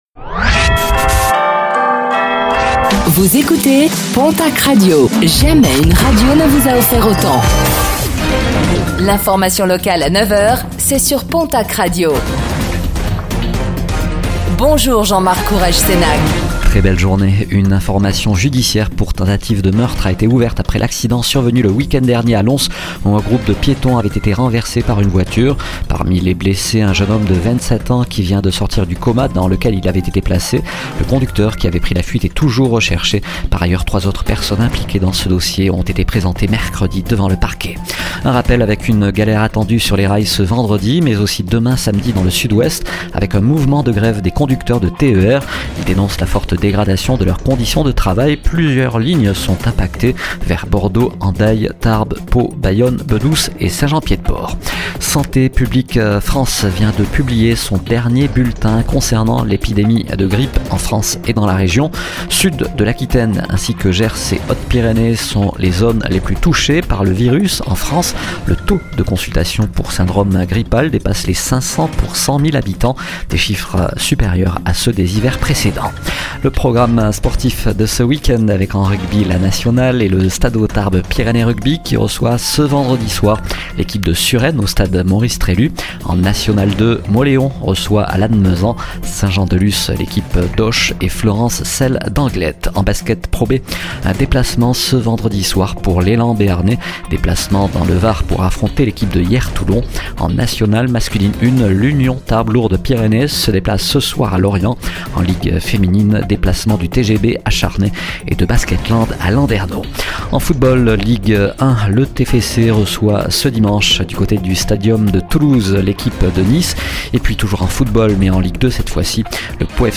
Réécoutez le flash d'information locale de ce vendredi 31 janvier 2025